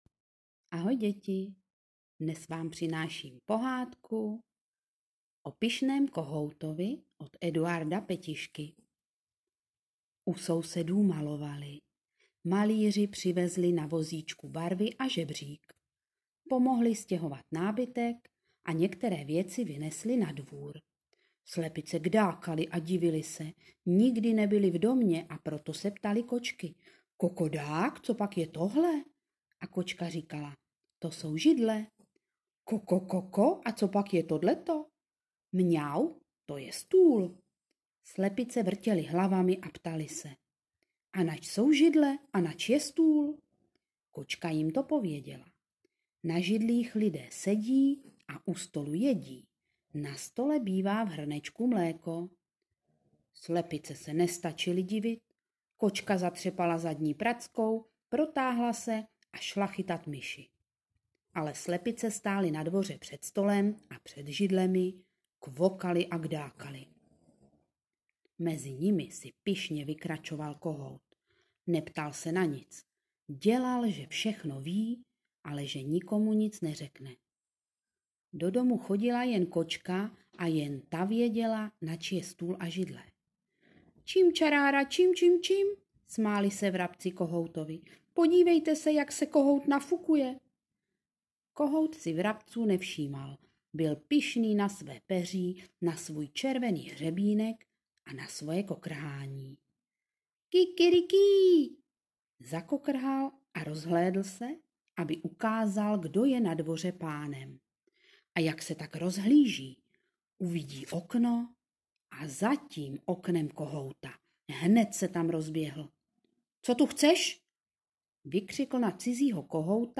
Pohádka: Pyšný kohout (nutné stáhnout do počítače a přehrát ve WMP)